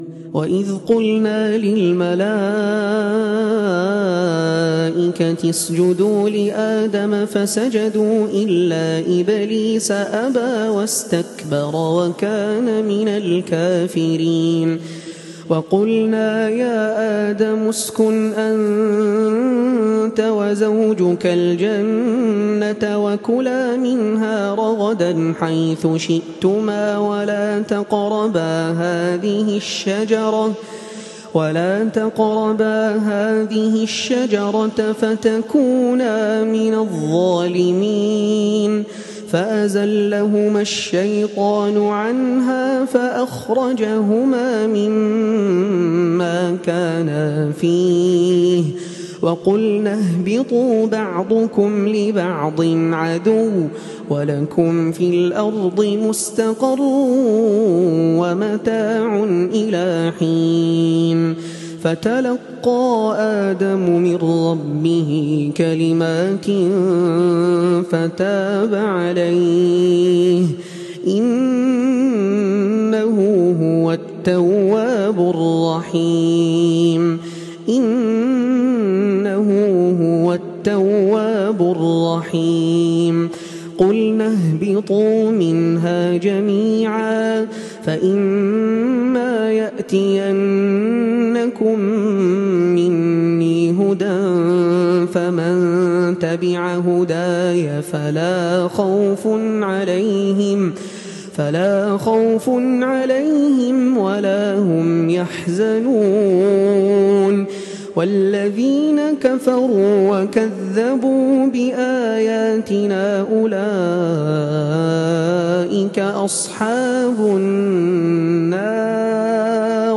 تلاوة خاشعة
من تراويح هذا العام ١٤٤٤هـ
تلاوة من صلاة التراويح لعام ١٤٤٤هـ من سورة البقرة للقارئ